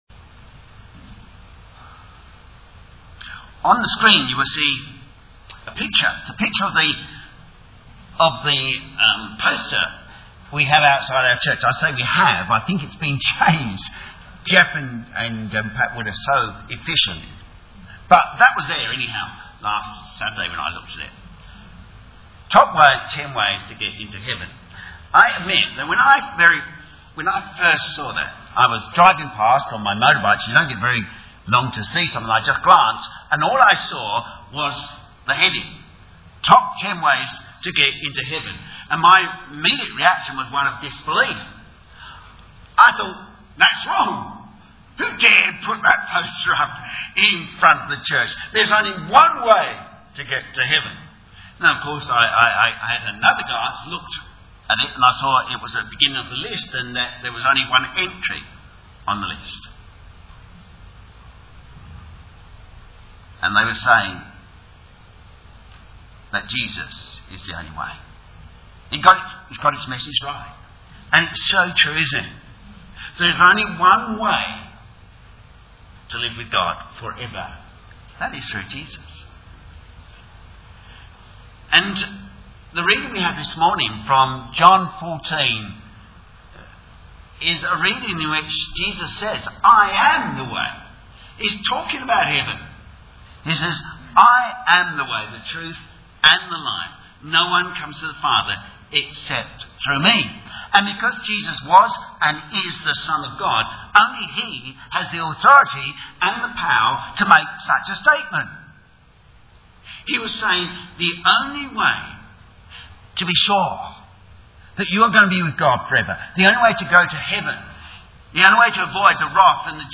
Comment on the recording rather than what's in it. BaptismalService-527.mp3